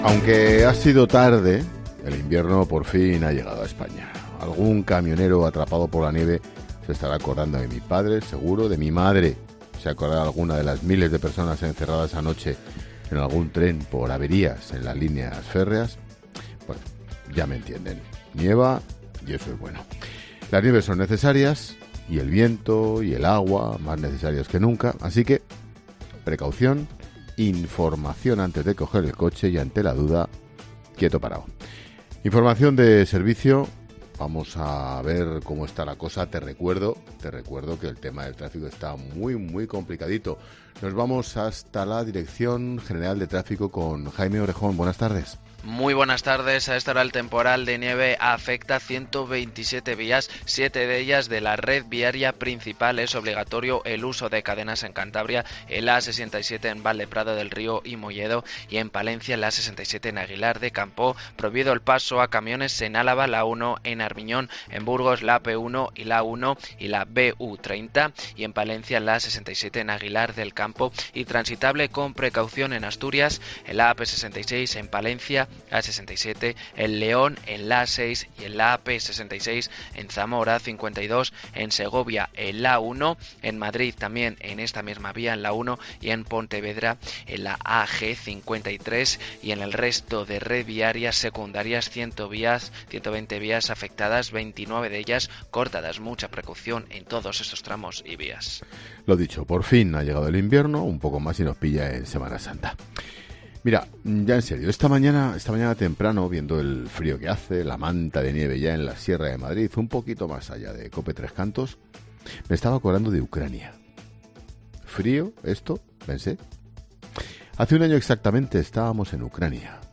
Monólogo de Expósito